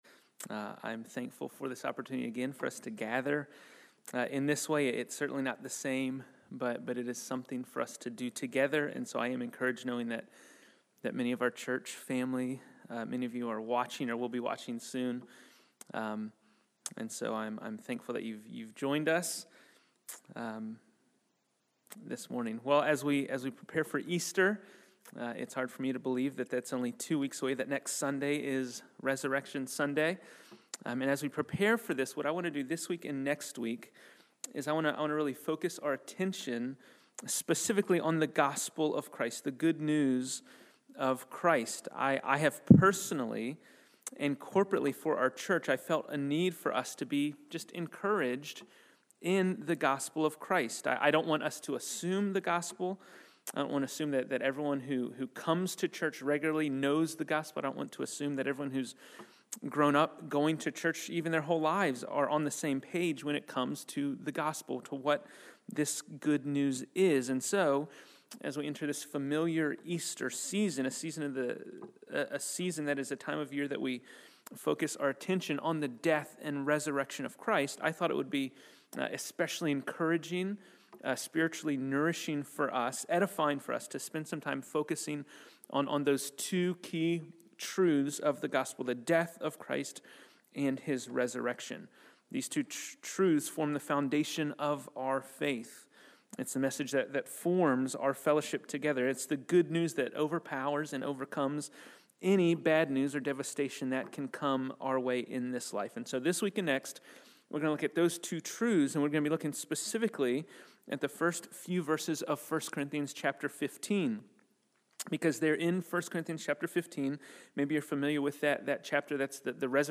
Fox Hill Road Baptist Church Sermons